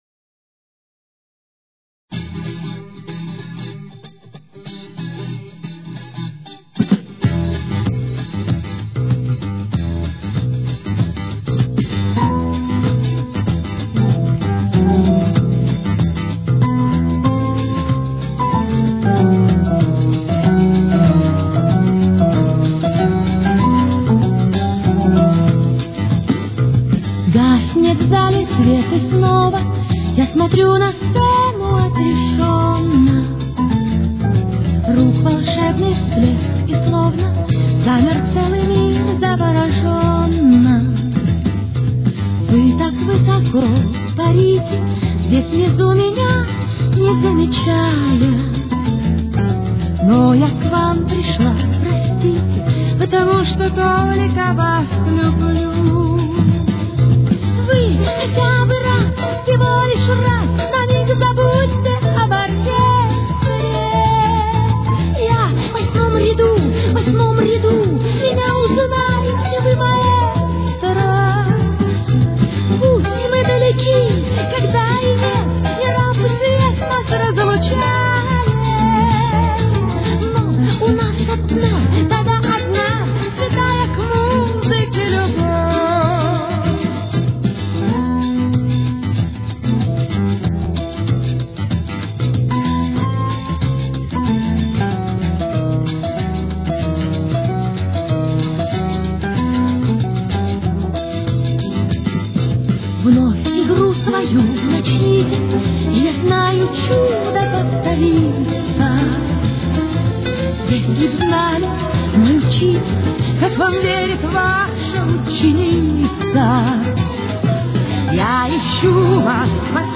с очень низким качеством (16 – 32 кБит/с)
Темп: 97.